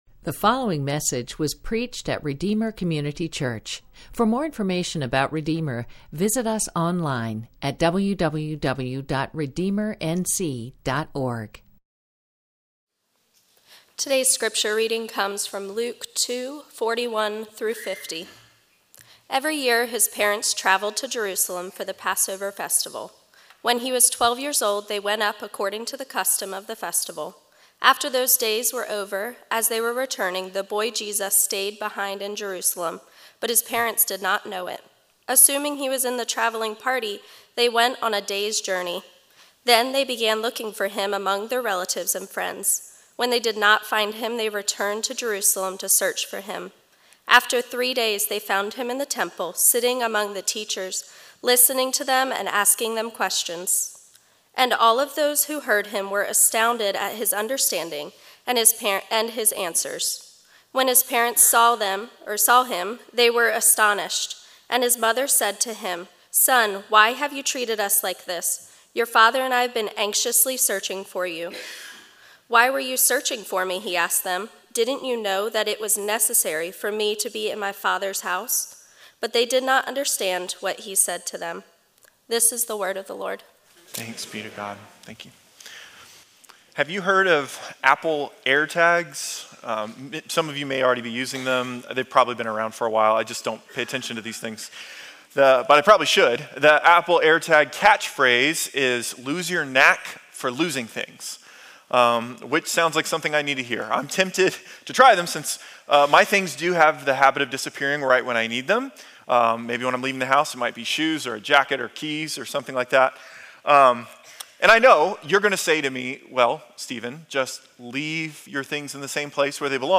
Sermons - Redeemer Community Church